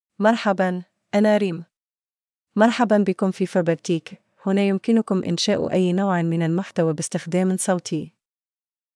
Reem — Female Arabic (Tunisia) AI Voice | TTS, Voice Cloning & Video | Verbatik AI
Reem is a female AI voice for Arabic (Tunisia).
Voice sample
Listen to Reem's female Arabic voice.
Reem delivers clear pronunciation with authentic Tunisia Arabic intonation, making your content sound professionally produced.